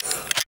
ht-locomotive-close.ogg